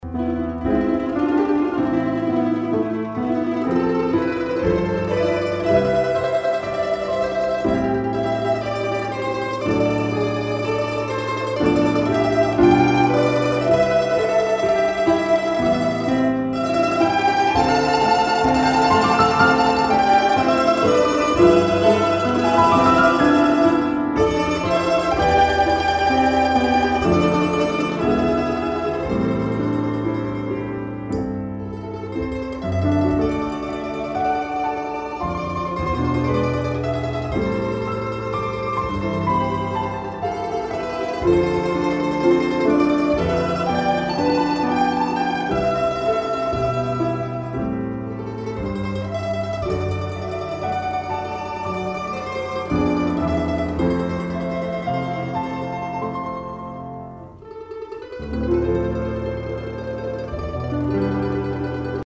Первая (октава 012 + Long pre) на ансамбле домр (5 шт. играют дети) 1.5-2 метра. ВТорая стереопара (neumann U87 + neve 1073) кругов на рояле, около 1 метра. Играем все вместе, разумеется. Запись совершенно сухой исходник.. ps. вообще в данной записи ещё стоят микрофоны пара акг 451 на рояле прям близко, 30 см от струн. и на каждую домру индивидуальные ленты Бурдукова.. Но в этом примере только 2 основные стереопары..